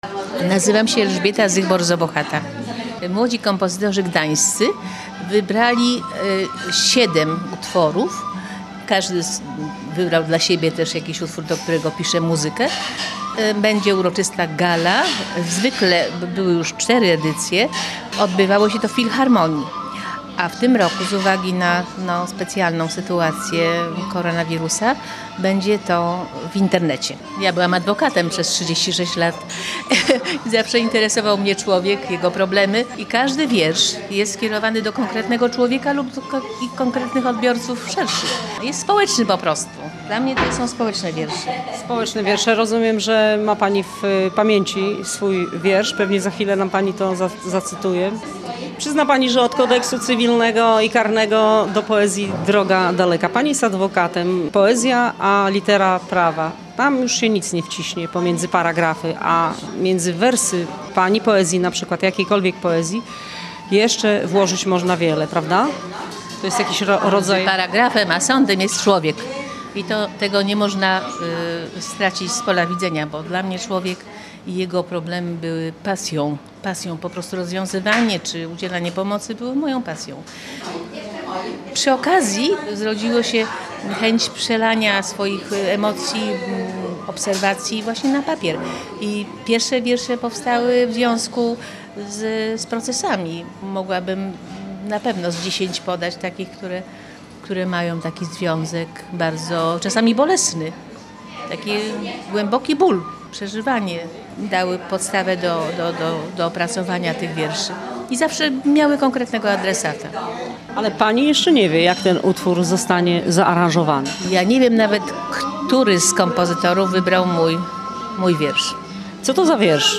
Rozmowy z autorami najlepszych wierszy